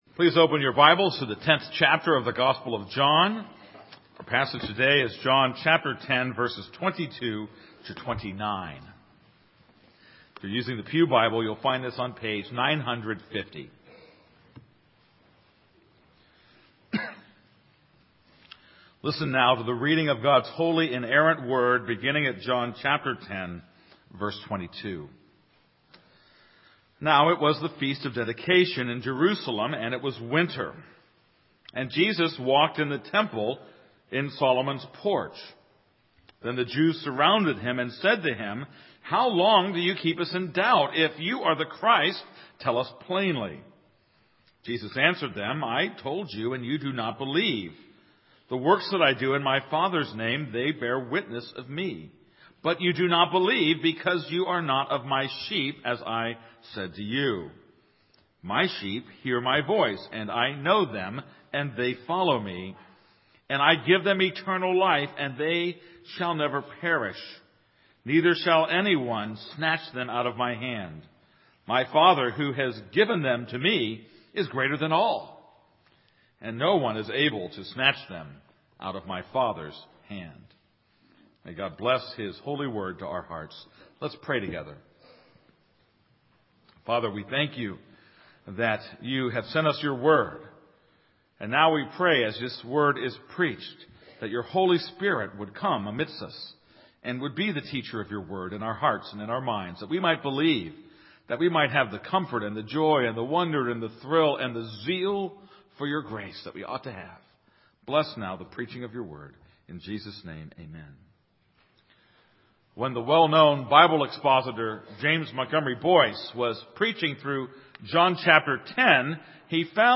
This is a sermon on John 10:22-29.